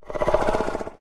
Angry Chimera Growls
tb_growls